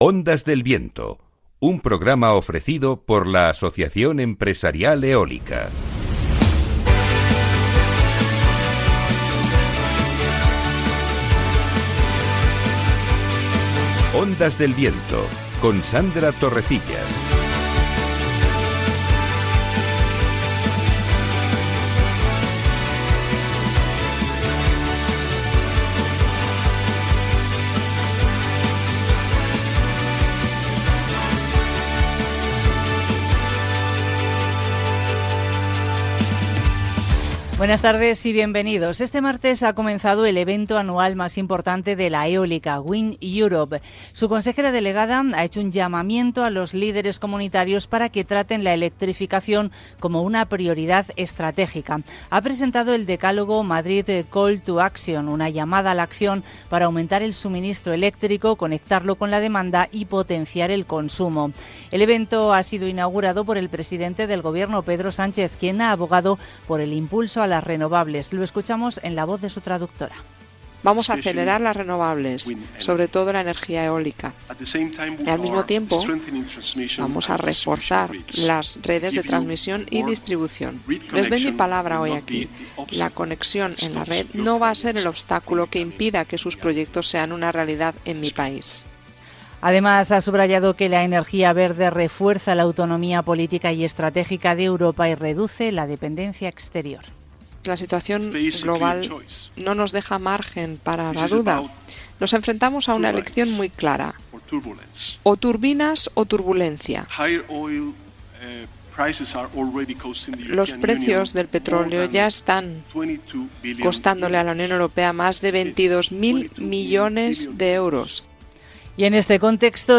🎙Hoy 21 de abril, hemos emitido un nuevo episodio de Ondas del Viento, el programa radiofónico del sector eólico en la emisora Capital Radio.
🎙En el marco de este congreso, el programa Ondas del Viento se ha desplazado para conocer de cerca la realidad industrial.